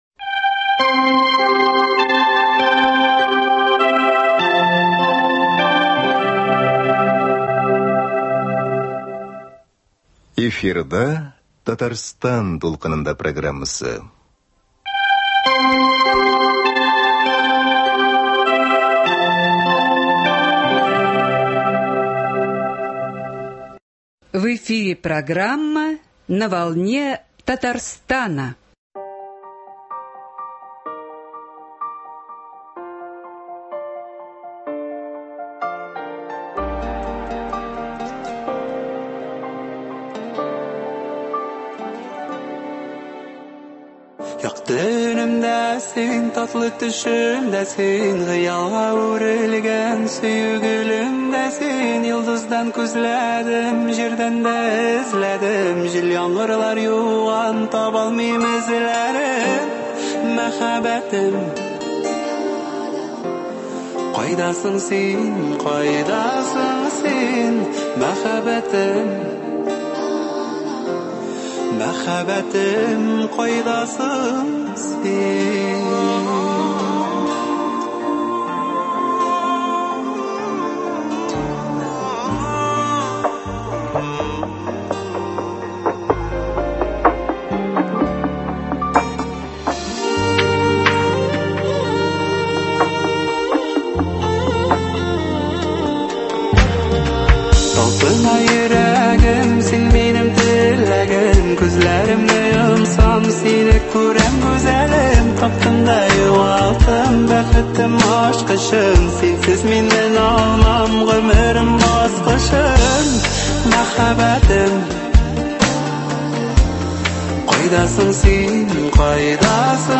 Беседа о тенденциях в современной татарской музыкальной культуре, образовании и о возможностях продвижения молодых исполнителей.